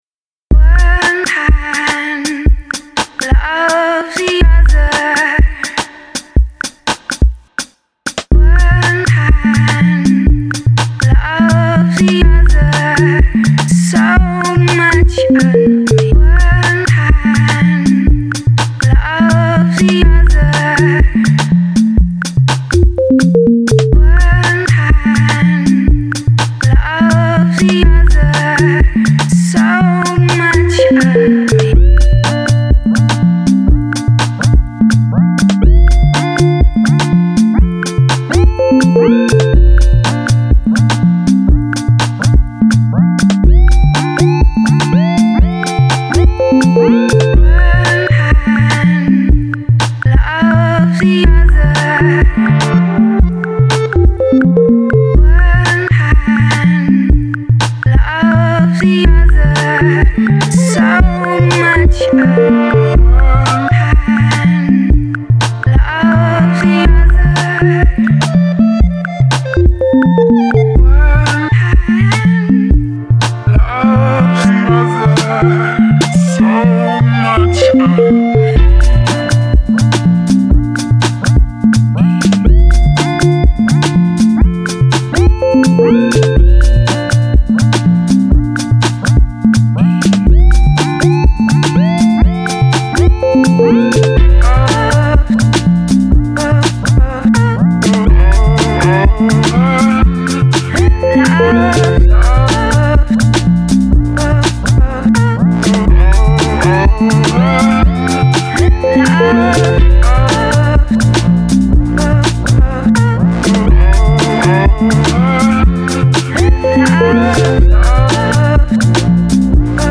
often ambient pop electronic.